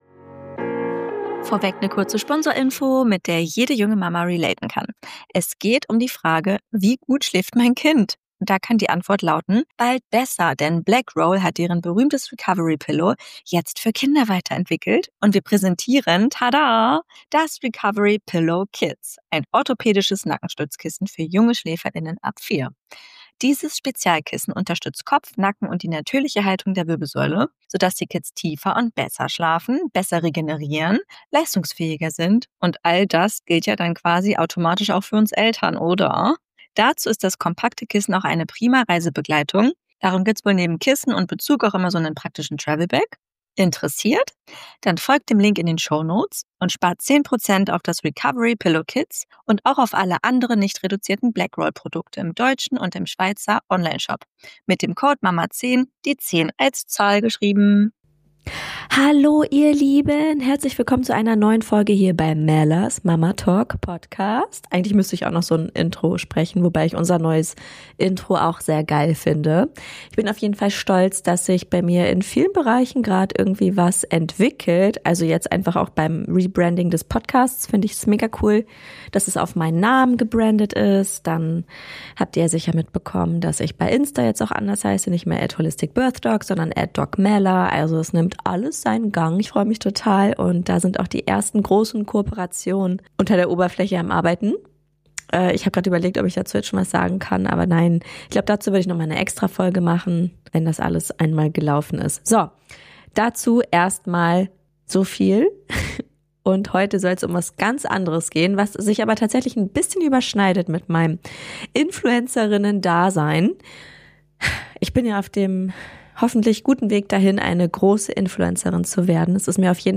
In dieser Folge lese ich euch Beispiele aus meinen DMs vor und zeige, wie ich aus Hate inzwischen Content mache. Gleichzeitig spreche ich über das große Ganze: Misogynie, Alltagssexismus, Gewalt gegen Frauen und den massiven Einfluss dieser Strukturen auf alle Mütter und Kinder.